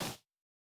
Bare Step Snow Hard D.wav